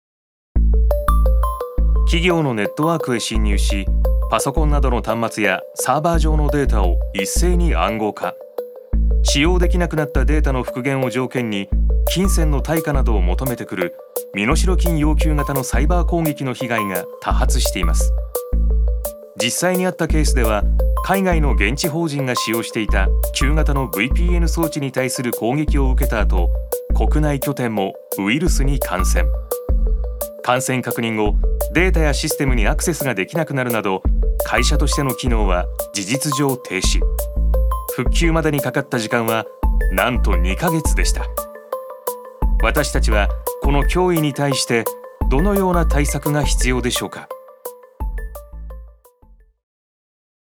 所属：男性タレント
ナレーション５